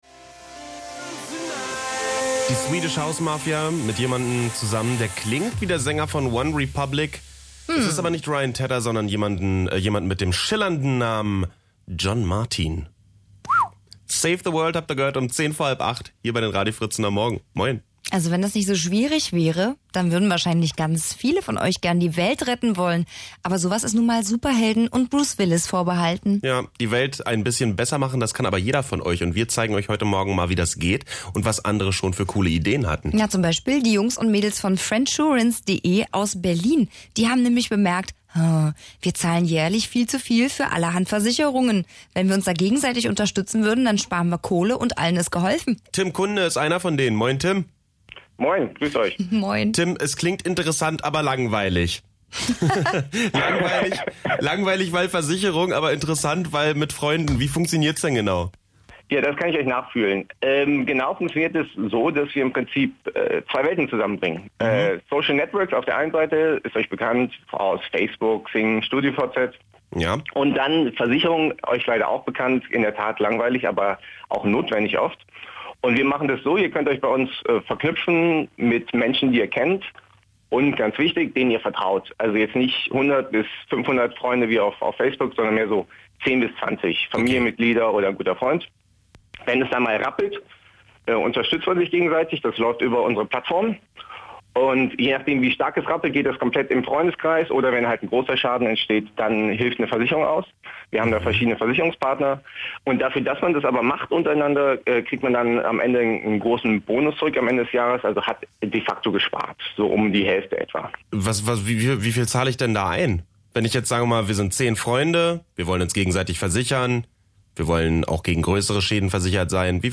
Fritz_Interview_friendsurance.mp3